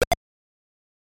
Здесь вы найдете как классические 8-битные эффекты из первых игр серии, так и более современные аудиофрагменты.
Марио звук выстрела